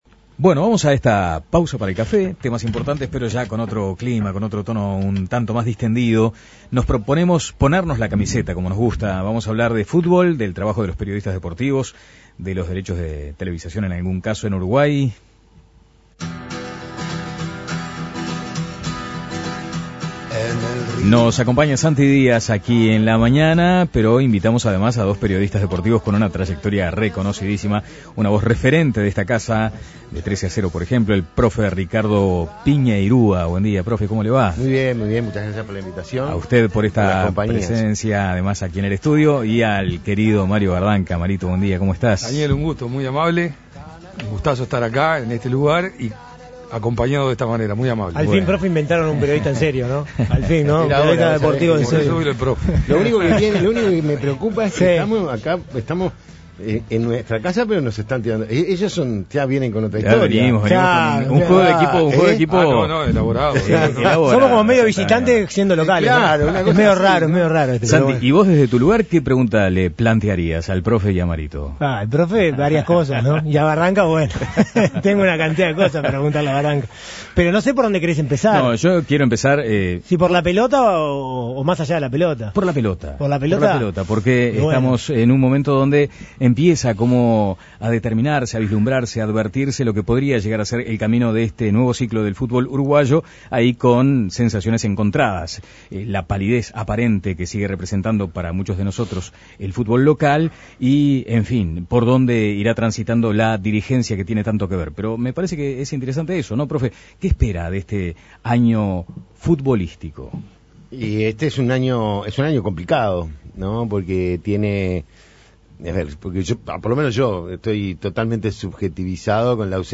En esta nueva propuesta de La Mañana de El Espectador, donde se propone tomar unos minutos sobre los temas que hacen a la cotidianidad en un clima relajado, participaron dos periodistas de destacada trayectoria como